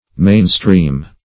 mainstream \main"stream`\ n.